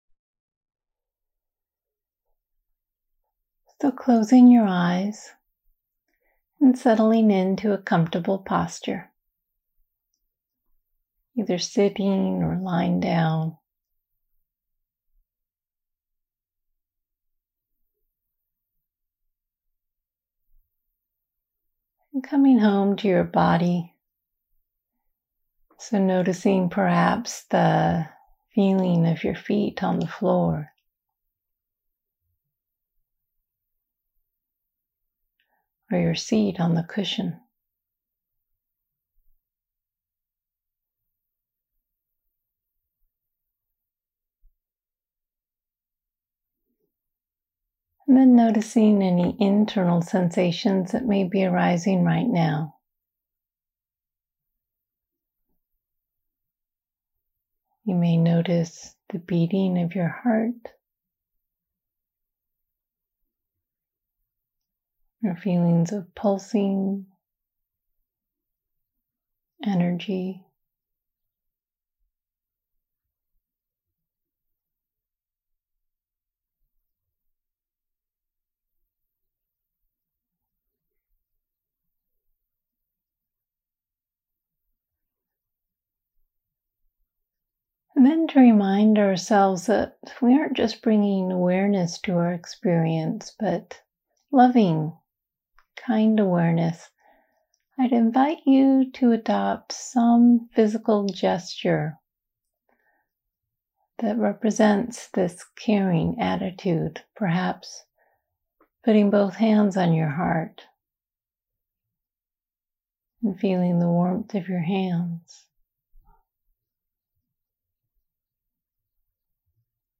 This meditation uses the breath to metaphorically give and receive compassion.